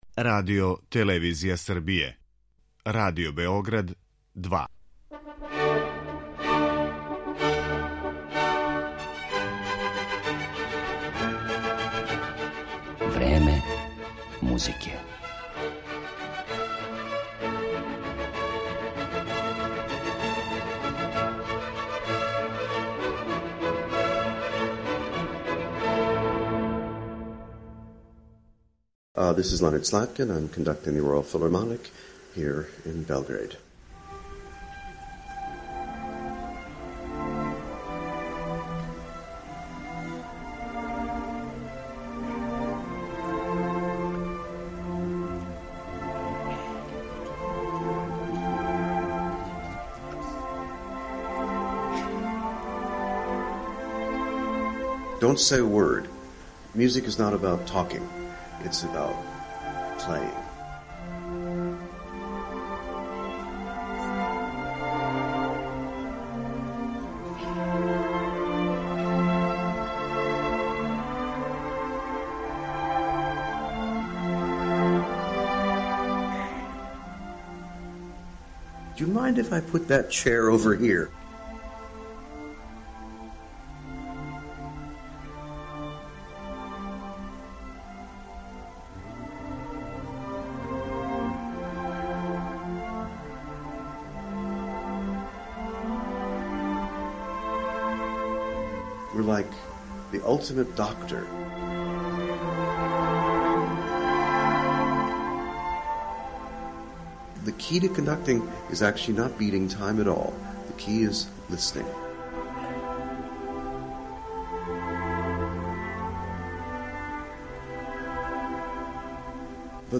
Године 2007. са Краљевским филхармонијским оркестром из Лондона гостовао је и у Београду и том приликом снимљен је интервју са овим великим америчким музичарем који ћете моћи да чујете у емисији.